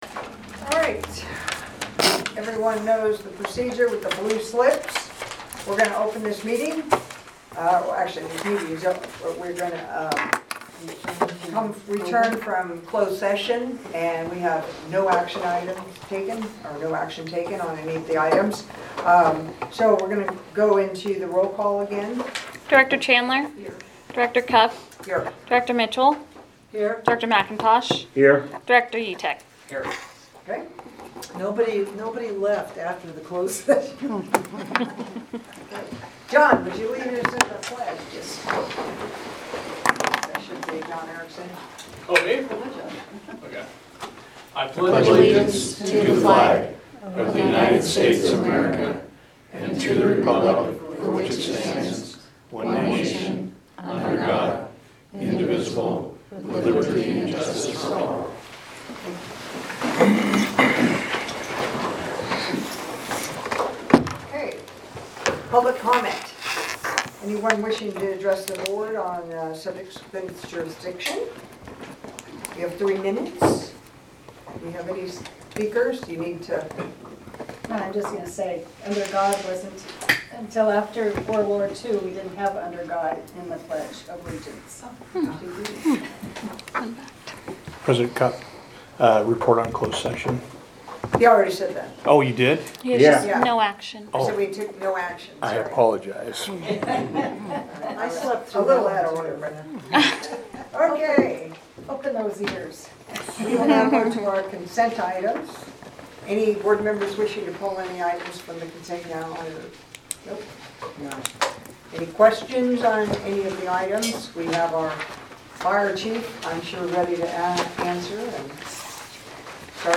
The LSCSD Board of Directors meets monthly on the third Wednesday at 1:00 p.m. at the Administration Building.
Board Meeting